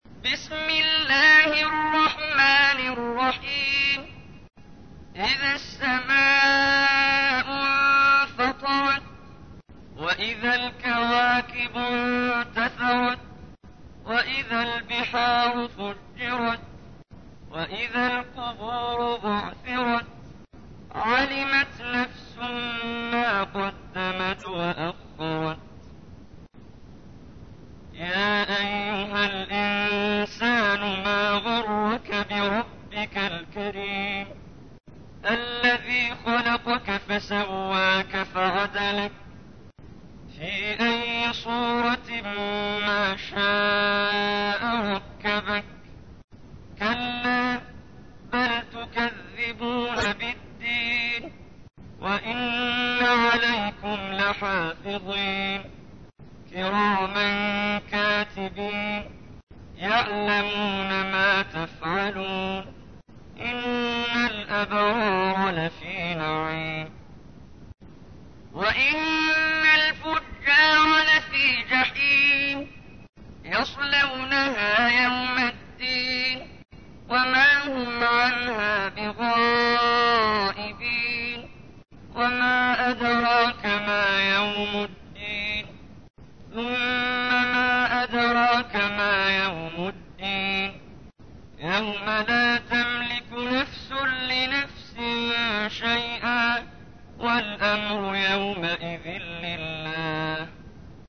تحميل : 82. سورة الانفطار / القارئ محمد جبريل / القرآن الكريم / موقع يا حسين